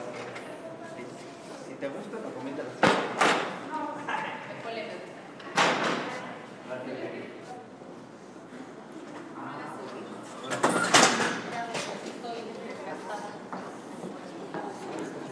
Los sonidos del final de cabildo